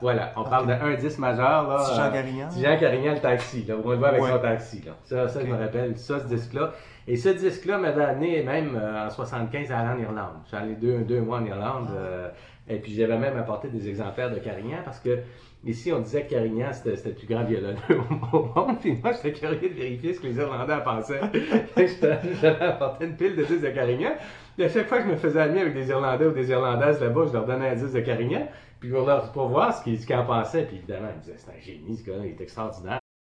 Extraits sonores des invités
Extraits des entrevues sur le patrimoine sonore :